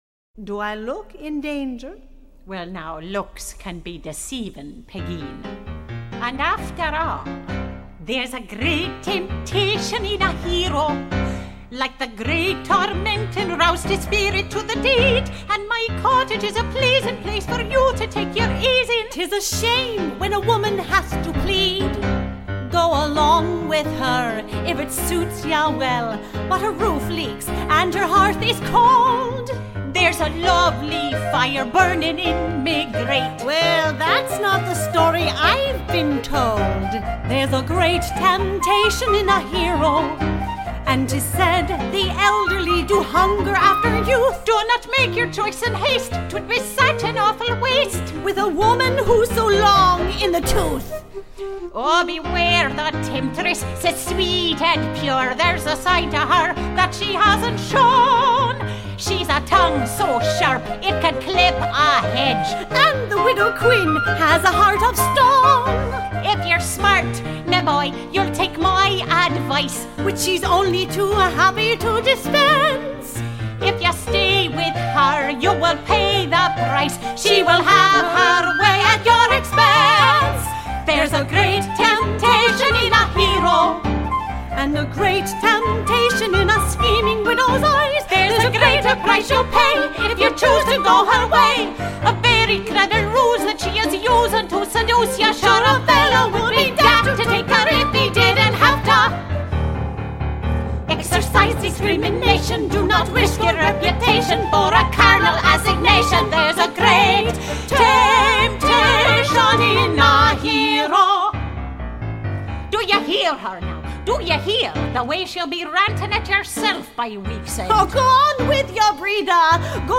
- An Irish Musical Comedy
(Widow, Pegeen)